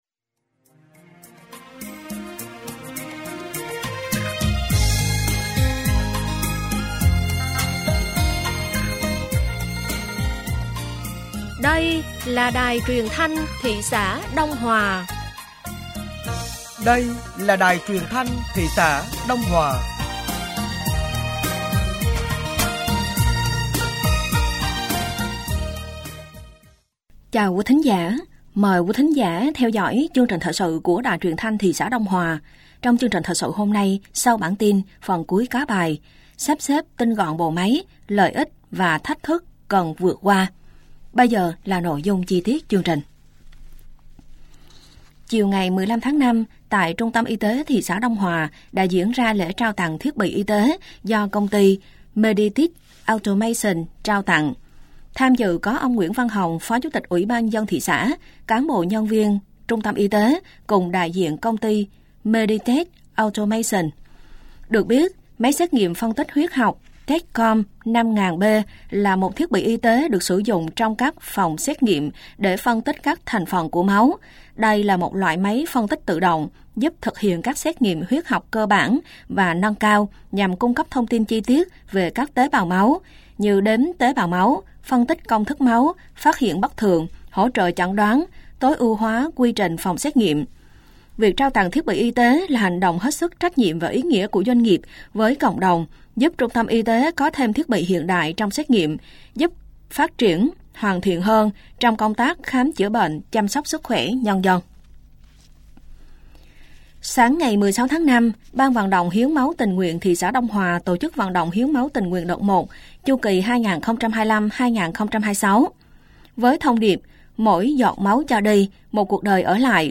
Thời sự tối ngày 16 và sáng ngày 17 tháng 5 năm 2025